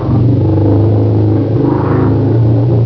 Elefant asiàtic -
Crit d'elefant asiātic
elefasia.au